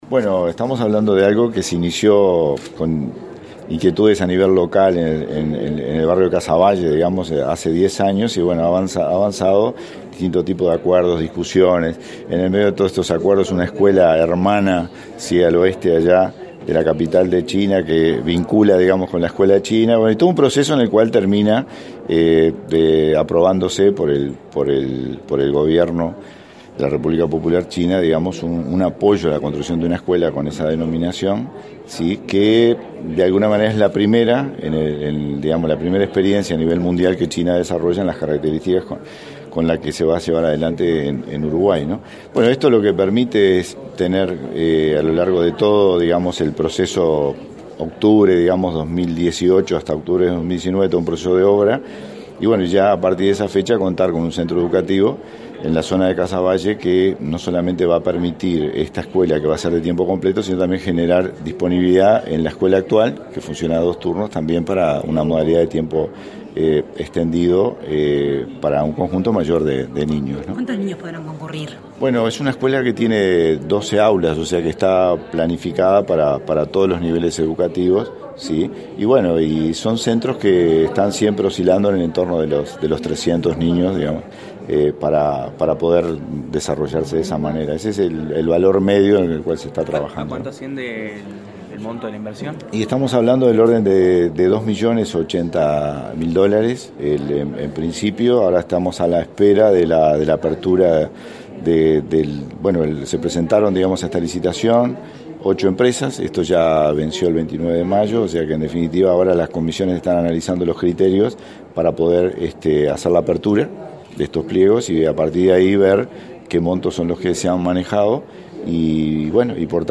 El presidente del Codicen, Wilson Netto, informó a la prensa que la obra comenzará en octubre y se ejecutará en un año. La escuela tendrá 1.600 metros cuadrados y 12 aulas y será construida por una empresa uruguaya.